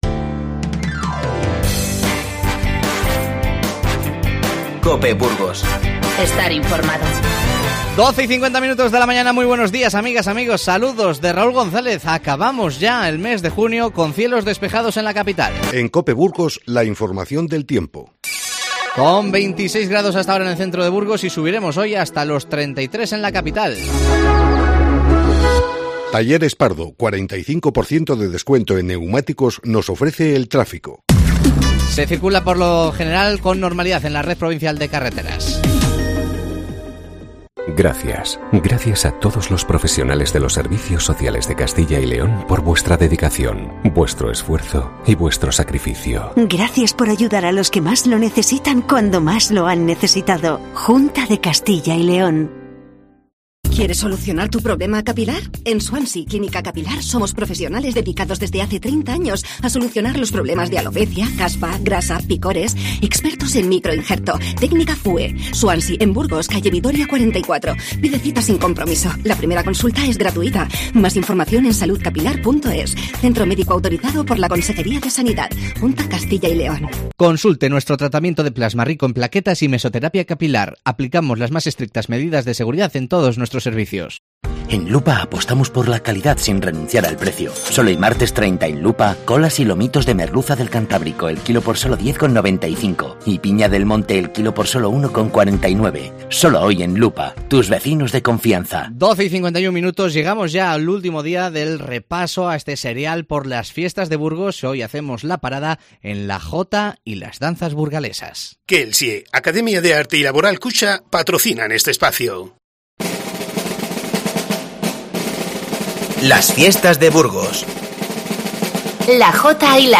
Escuchamos el octavo y penúltimo reportaje de fiestas para recordar lo más característico de los Sampedros, silenciados por el coronavirus. El protagonismo lo tiene la Jota y las Danzas Burgalesas.